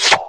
BP_14_SFX_Rope_Attach.ogg